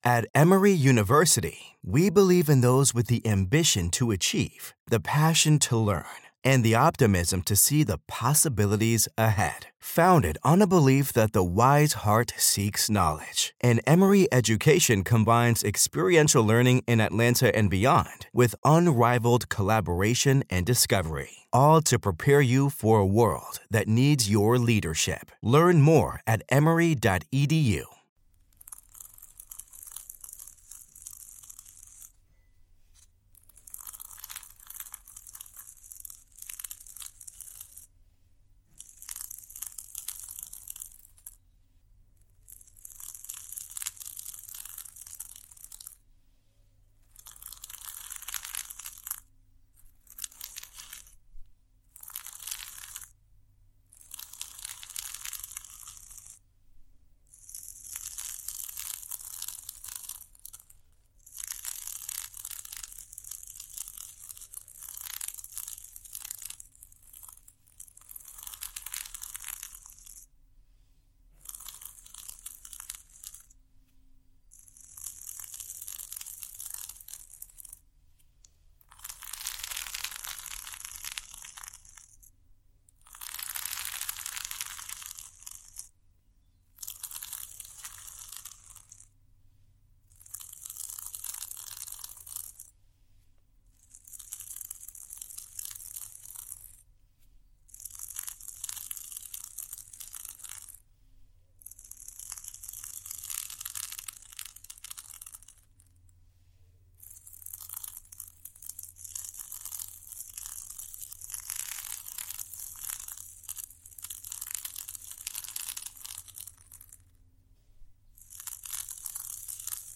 Our carefully curated episodes feature soothing whispers, gentle tapping, and immersive binaural sounds designed to calm your mind, improve sleep, and bring balance to your day.
From soft-spoken affirmations to delicate hand movements, we blend ambient triggers and immersive soundscapes to create a space where you can experience the full effects of ASMR.